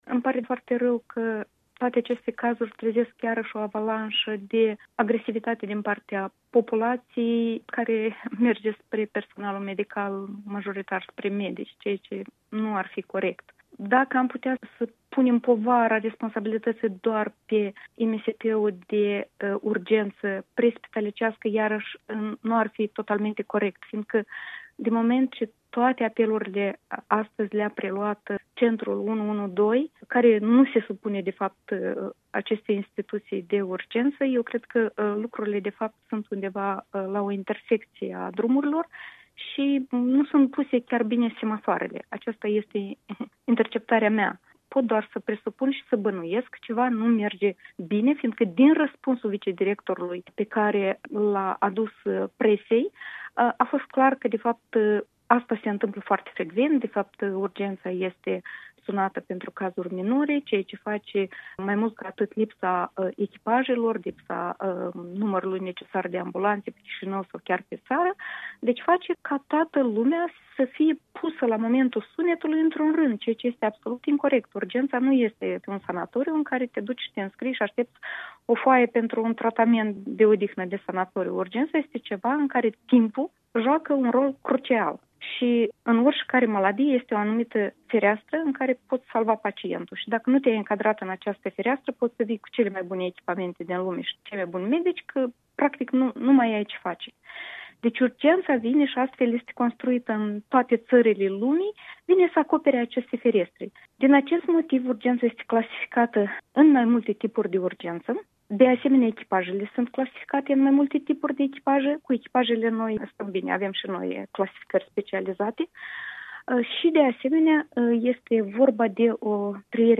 Urgența nu este un sanatoriu în care te duci și te înscrii - un interviu cu Ala Nemerenco